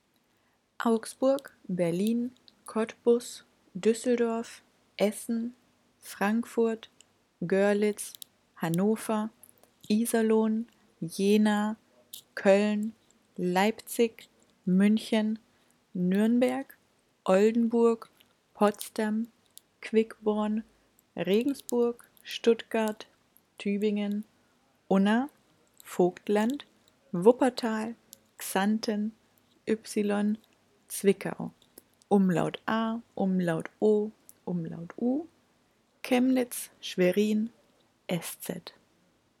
Die Aussprache aller Städte hier: